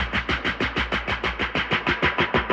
RI_DelayStack_95-01.wav